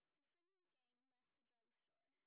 sp18_street_snr30.wav